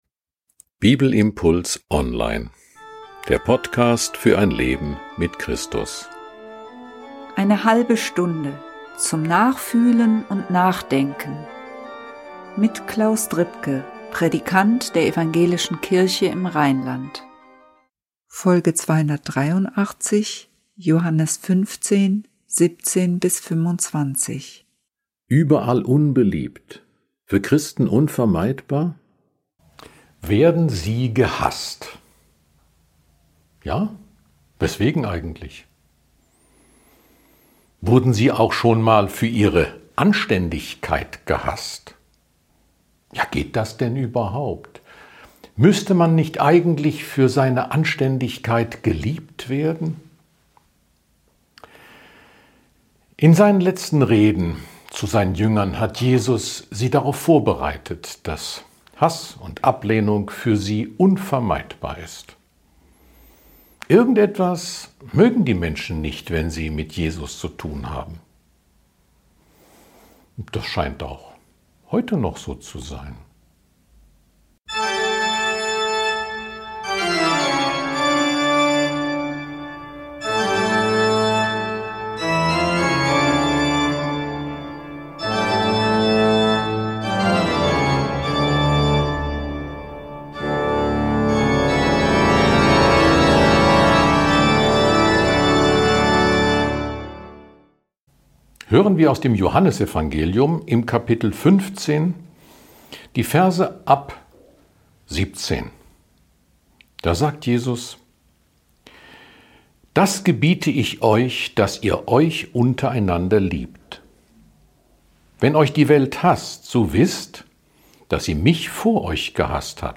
Warum werden sie dennoch so häufig abgelehnt? Jesus weiß, warum und sagt es auch seinen Jüngern. Ein Bibelimpuls zu Johannes 15, 17-25.